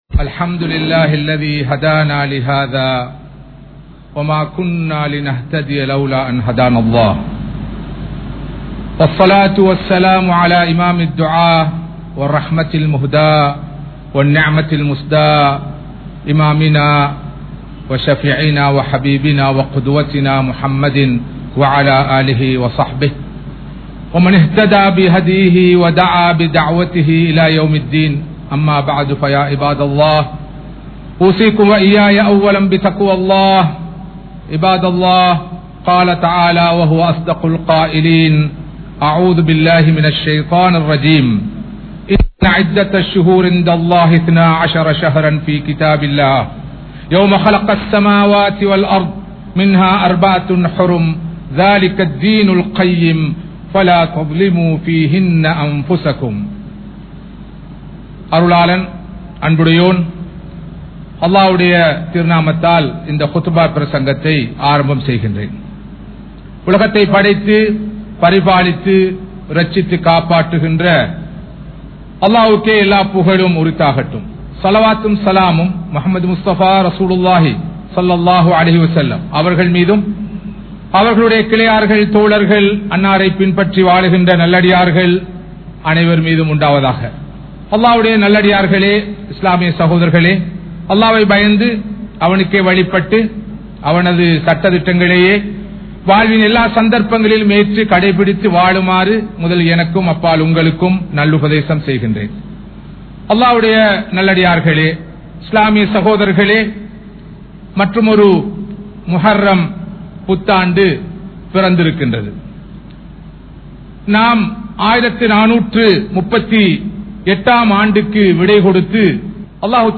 Muharram & Hijrath (முஹர்ரம் & ஹிஜ்ரத்) | Audio Bayans | All Ceylon Muslim Youth Community | Addalaichenai